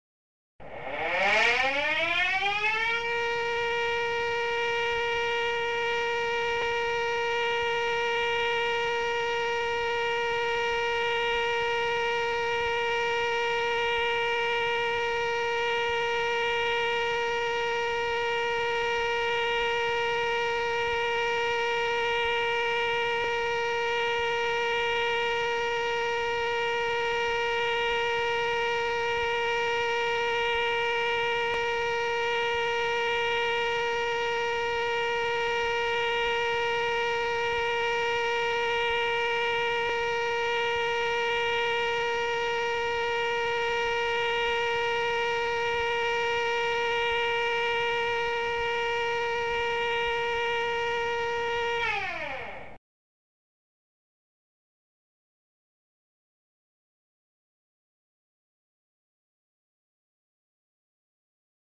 ※音が鳴ります、音量にご注意ください。